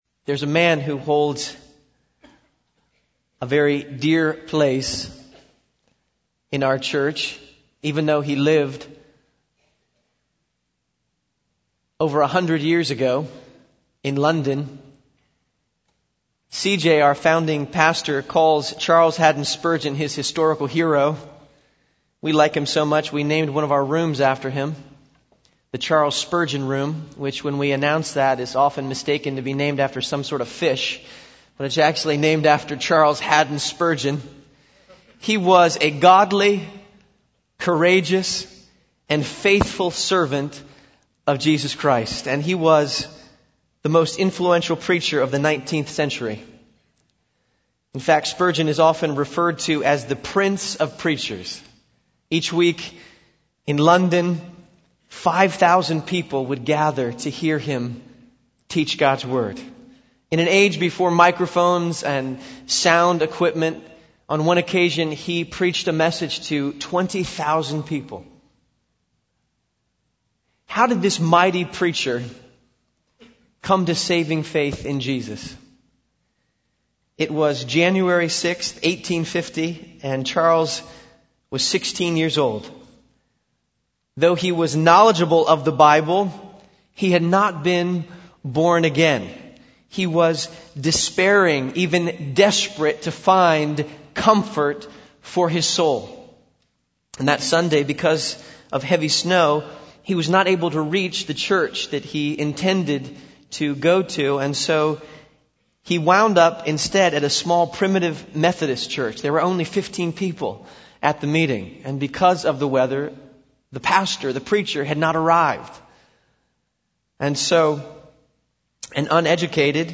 Josh Harris wondrously preached this Gospel-centered living from 1 Corinthians 2:1-5 at his church on the September 17th.